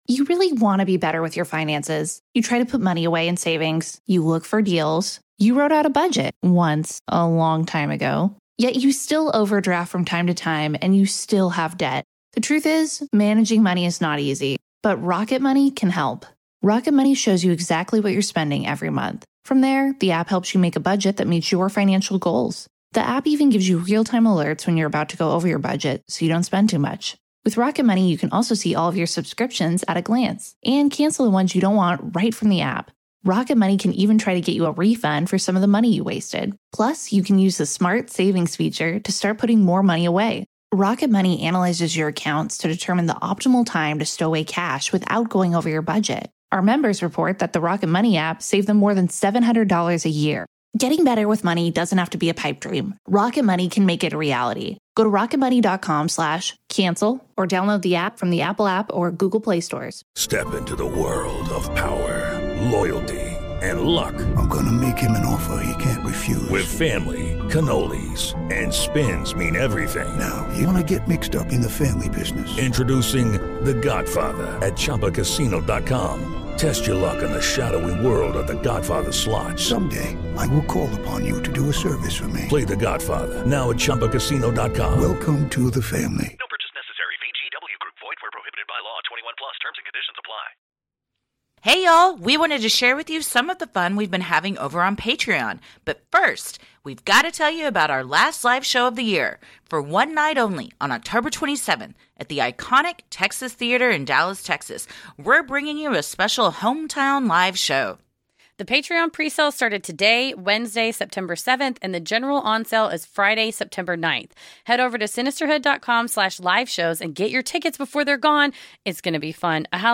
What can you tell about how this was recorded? If you aren't subscribed to our Patreon, we wanted to give you a glimpse of what we've got going on. This mega-mix of clips brings you some of our favorite segments from the month of August.